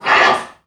NPC_Creatures_Vocalisations_Robothead [16].wav